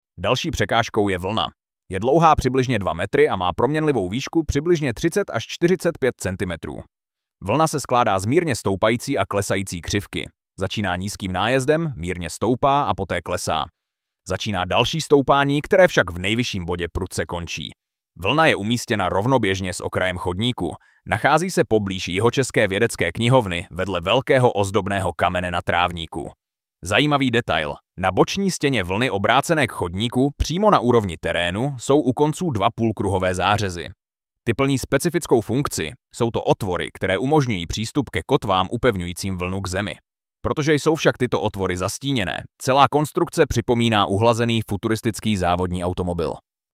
AUDIOPOPIS ZÍDKA VE TVARU VLNY